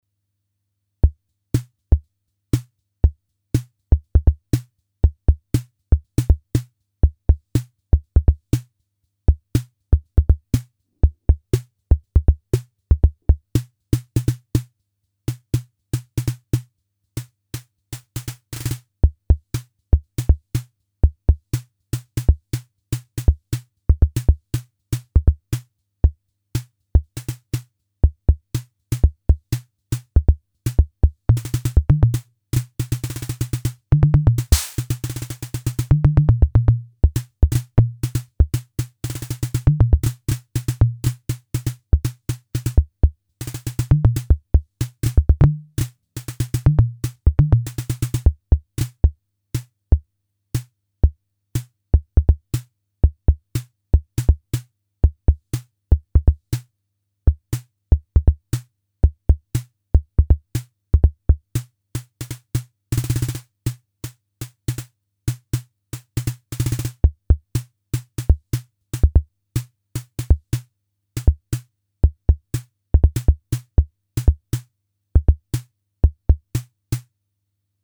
Portable analog drum machine with sequencer, Midi and some real-time knobs.
editDRUM The 502 voice board generates 8 analog percussions (some are channel-shared) each with individual mix gain:
demo original MFB demo